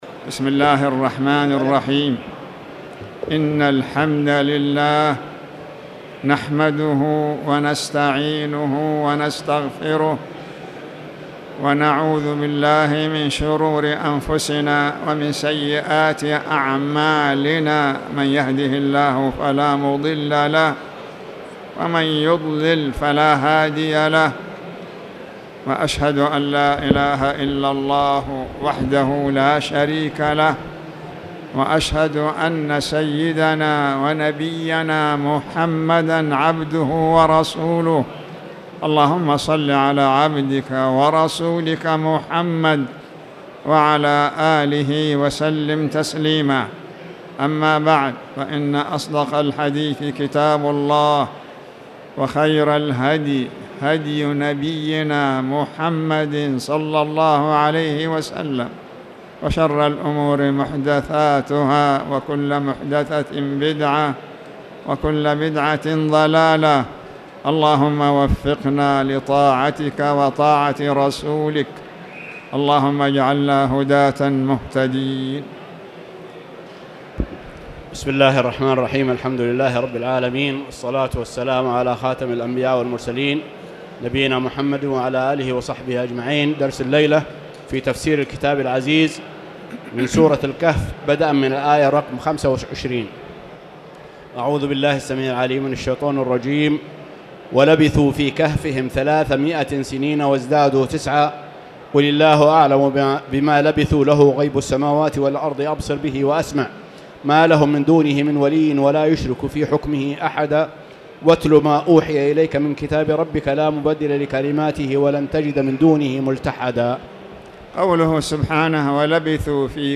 تاريخ النشر ٢٤ ذو الحجة ١٤٣٧ هـ المكان: المسجد الحرام الشيخ